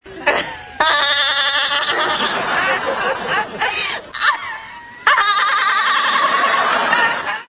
Personagem Janice da sitcom Friends solta sua risada chata e engraçada.
friends-janice-risada.mp3